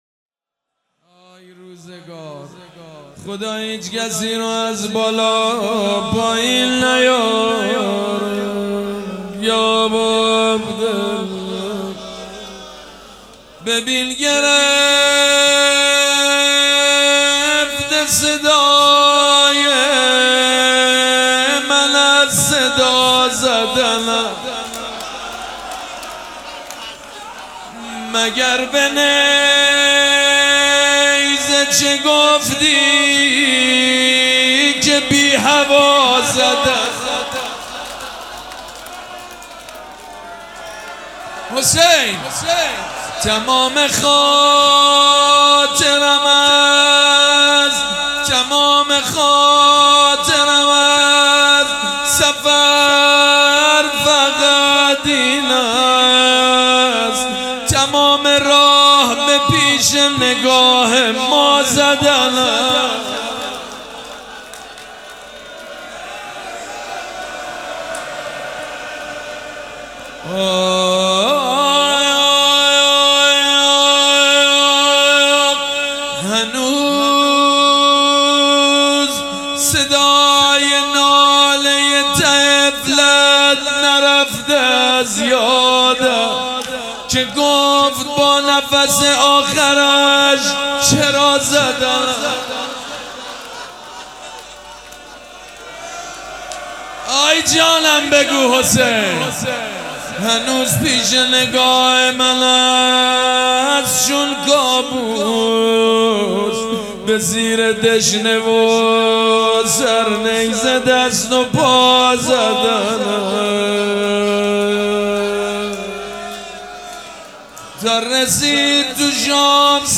روضه
مداح
مراسم عزاداری شب اول